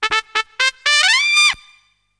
trompet.mp3